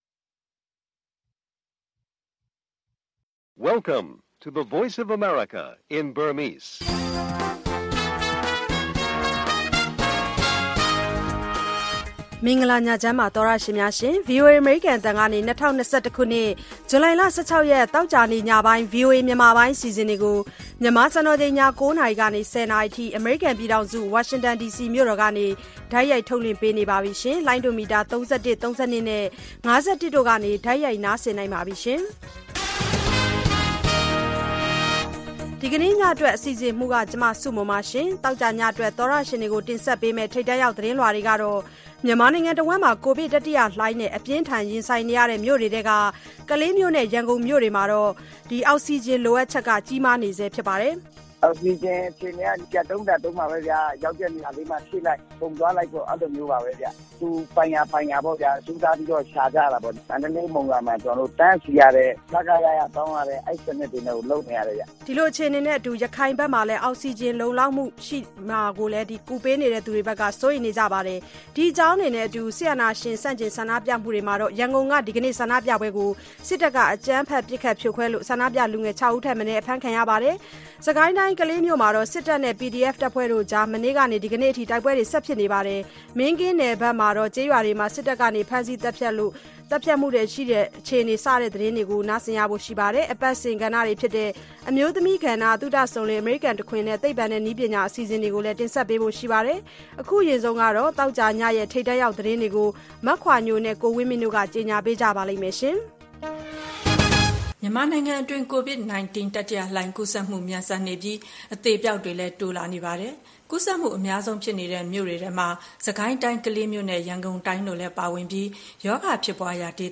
ကိုဗစ်အခြေအနေ နောက်ဆုံးရသတင်းများနဲ့အတူ သီတင်းပတ်စဉ်ကဏ္ဍတွေအပါအဝင် ဗွီအိုအေ သောကြာည ၉း၀၀-၁၀း၀၀ နာရီ ရေဒီယိုအစီအစဉ်